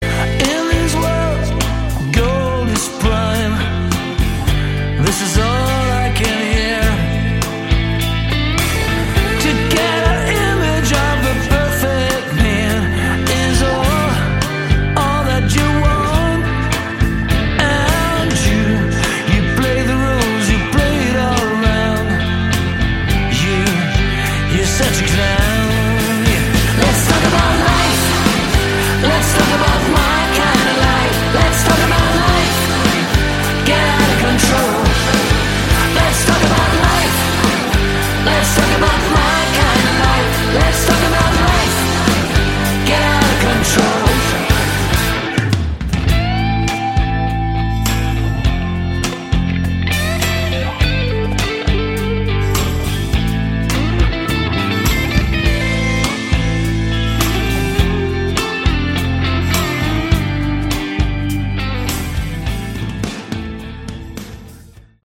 Category: AOR
lead Vocals
guitars
drums, backing vocals
bass
keyboards
backing and lead vocals
backing vocals, percussion